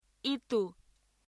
ここではまず，第2尾音節にアクセントがくるパターンの発音を練習しましょう。
練習　イメージを参考に，モデルに合わせて発音しましょう。